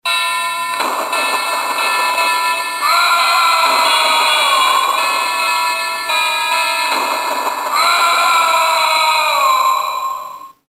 extremely loud "horrobal" death toll sound along with some tortured guy yelling in agony.